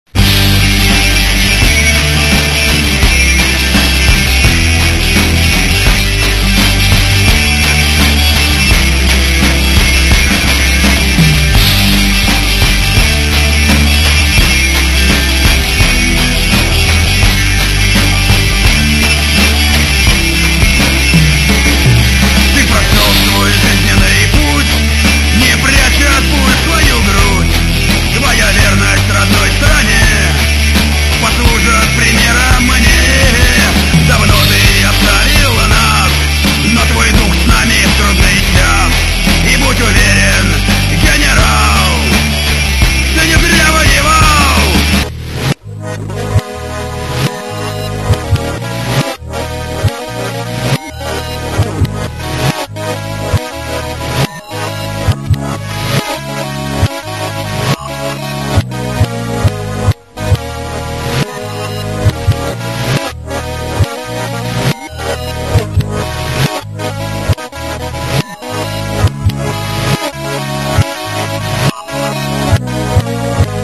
странную музыку